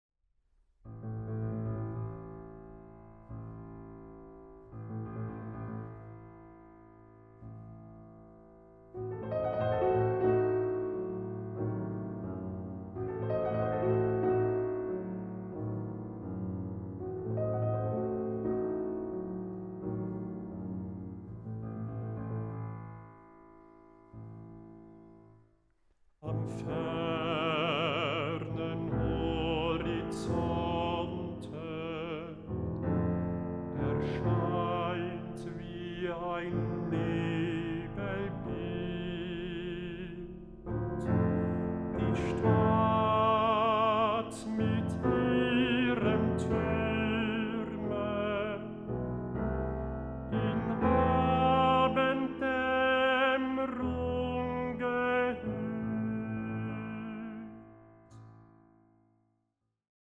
Bassbariton
Klavier
Aufnahme: Ölbergkirche Berlin, 2024